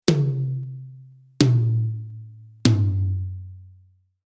Beispiele für die Mikrofonierung von Toms
Es bietet sich an, bei den Rack-Toms das Mikrofon für das linke Tom in den Bereich zwischen der Position "12 Uhr" bis "13 Uhr" anzubringen.